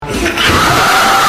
Grandma Scream Meme Original